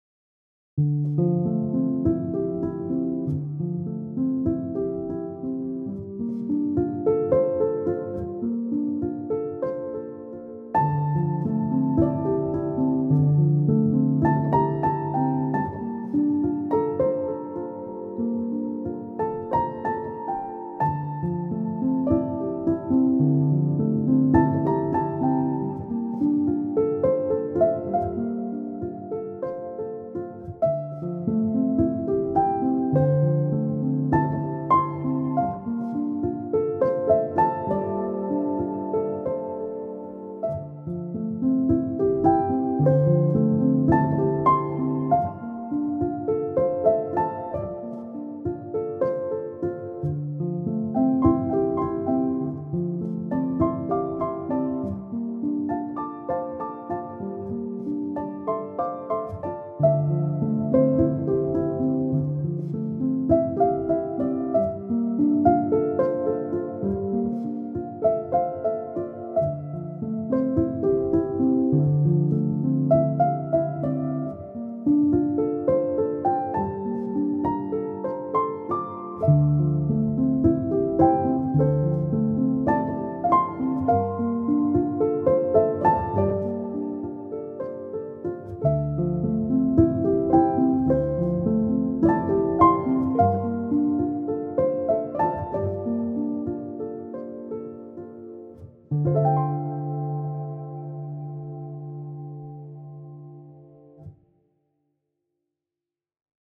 Ambient, Piano, Soundtrack, Emotive, Melancholic